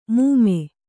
♪ mūme